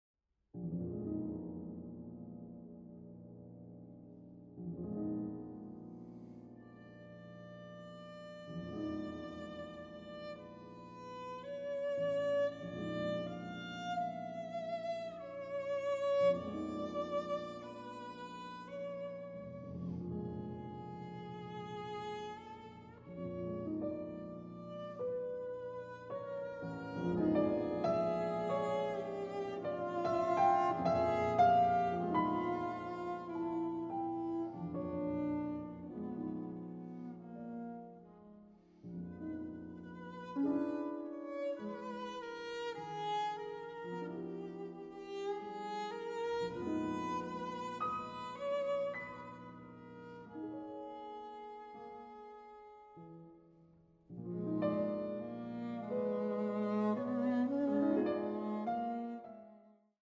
Viola
Piano
Lento